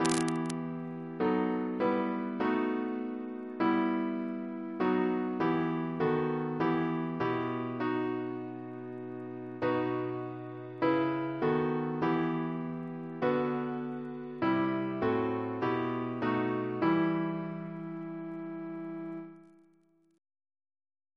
Double chant in E minor Composer: Roger R. Ross (1817-1899) Reference psalters: RSCM: 114